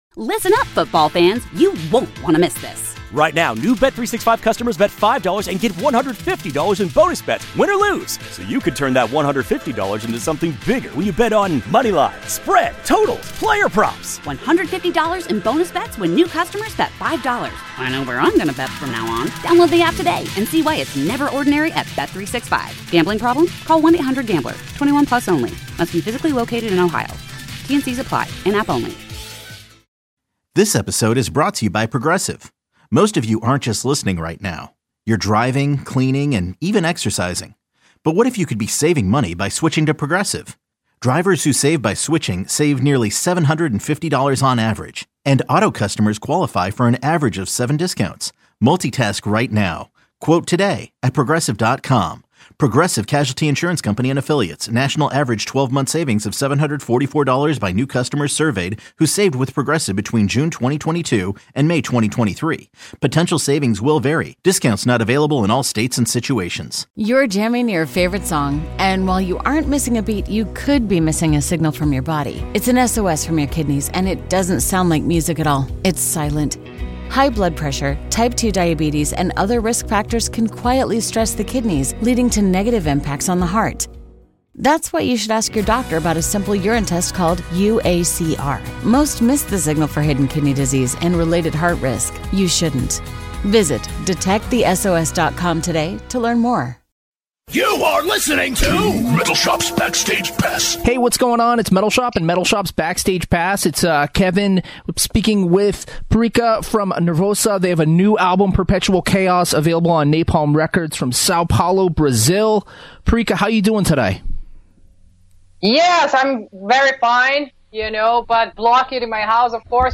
being the first all female metal band on the cover of Decibel and more in this short chat...